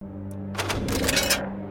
Select Clink Noise Wasteland 3